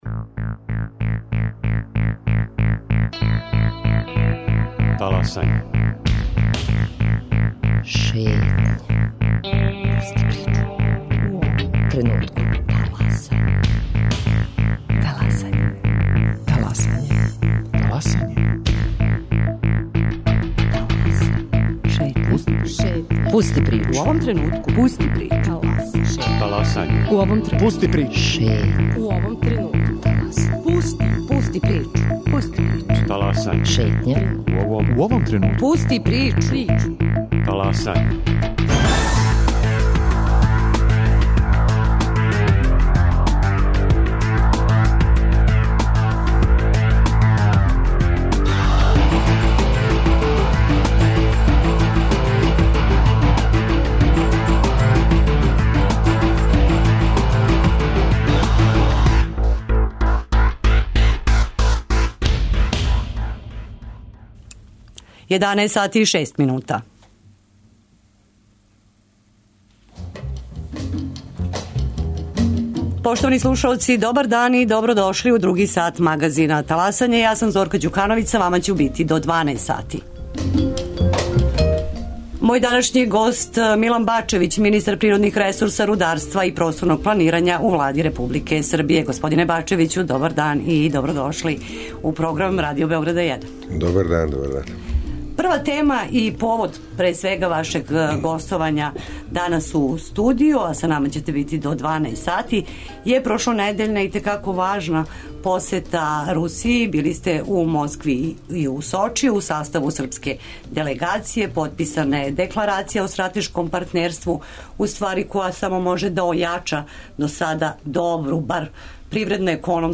По повратку из Москве, о стратешком партнерству са Русијом, говори Милан Бачевић, министар природних ресурса, рударства и просторног планирања у Влади Србије.